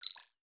Simple Digital Connection 5.wav